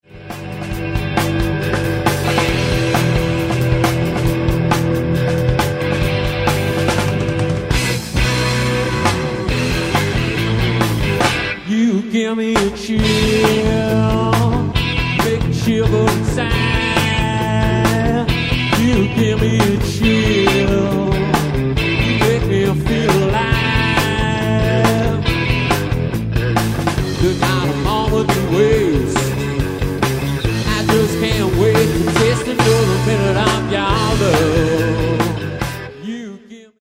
sweaty pounding rhythms